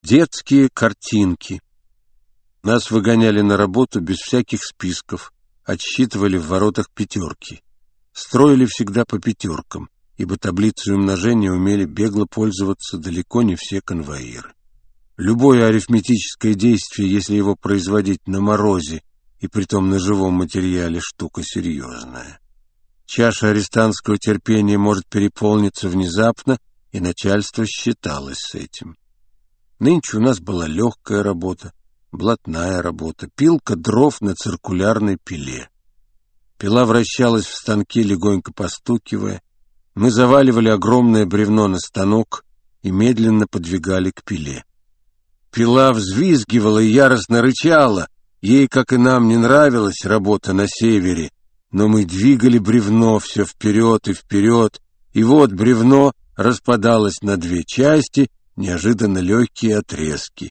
Аудиокнига Колымские рассказы | Библиотека аудиокниг